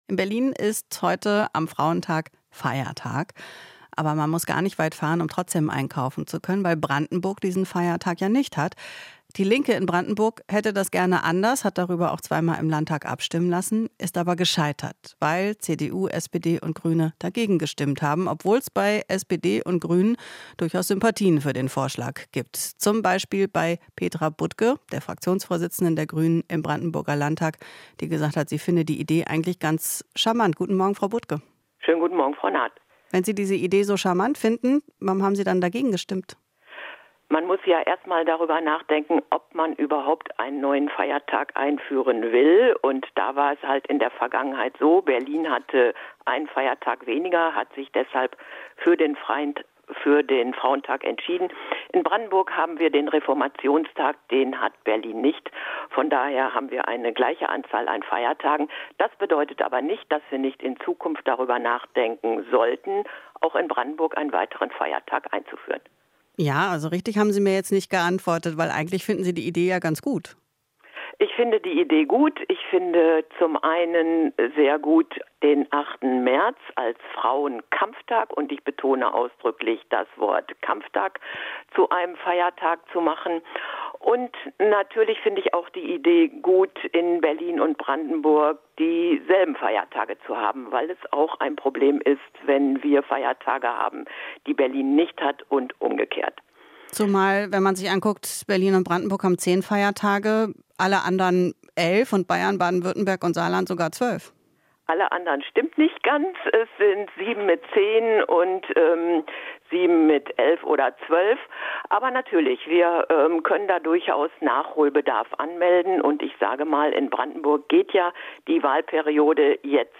Interview - Grünen-Fraktionschefin Budke: "8. März ist Frauenkampftag"